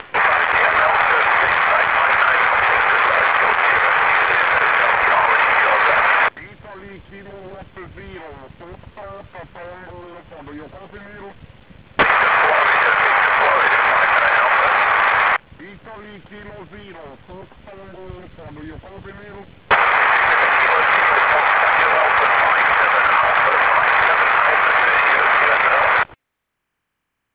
UNUSUAL AURORA IN JN61 SQUARE!